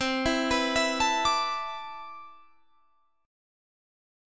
Listen to CM13 strummed